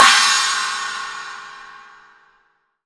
Index of /90_sSampleCDs/AKAI S6000 CD-ROM - Volume 3/Crash_Cymbal2/CHINA&SPLASH
CHINA.WAV